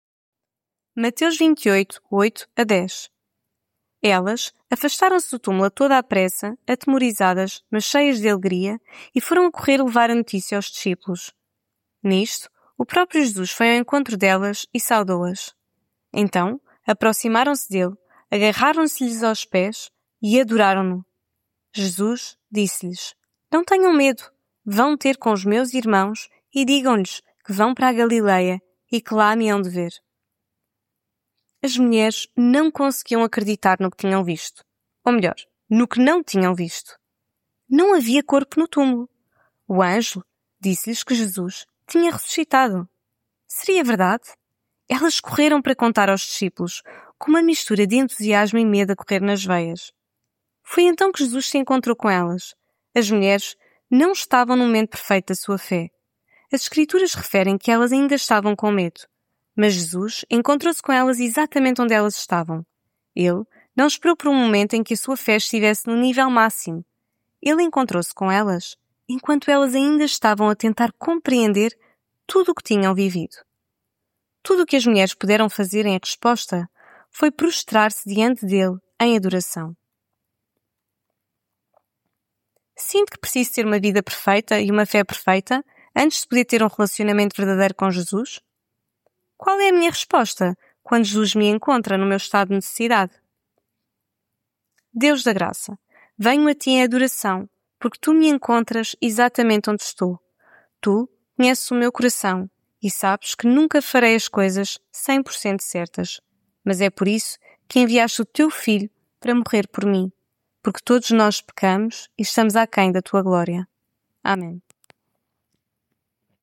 Devocional Quaresma